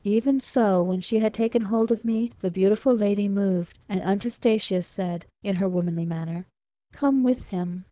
We take 100 test samples from the dev-clean subset of LibriTTS for testing.